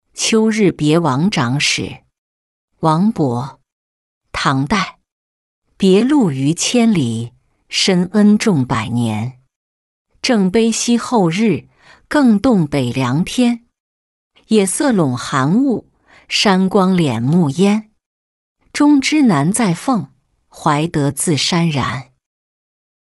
秋日别王长史-音频朗读